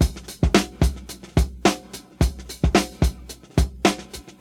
• 109 Bpm Breakbeat C Key.wav
Free drum groove - kick tuned to the C note. Loudest frequency: 1251Hz
109-bpm-breakbeat-c-key-Sip.wav